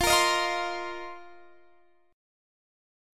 F7sus4 Chord
Listen to F7sus4 strummed